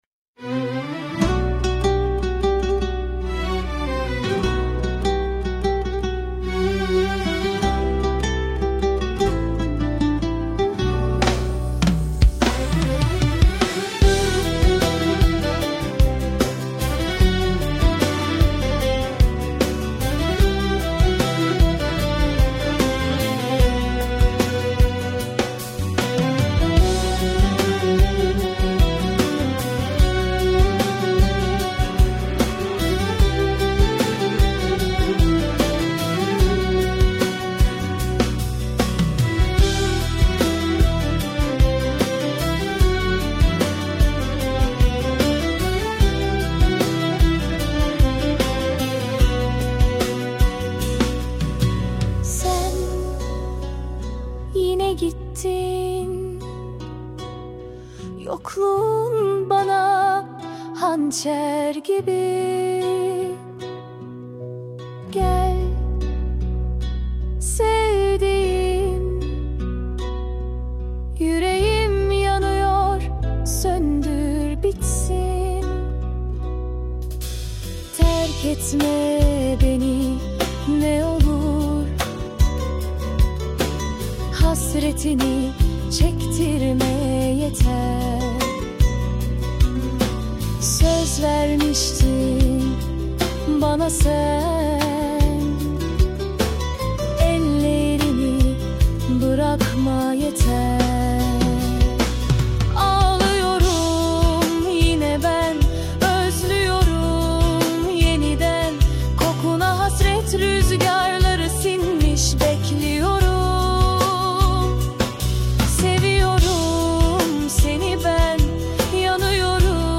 Tür : Akdeniz, Melankolik, Pop